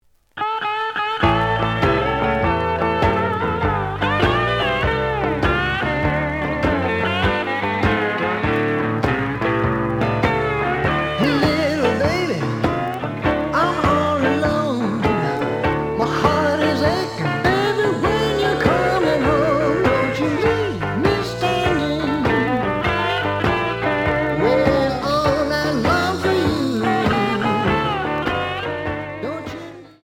試聴は実際のレコードから録音しています。
●Genre: Blues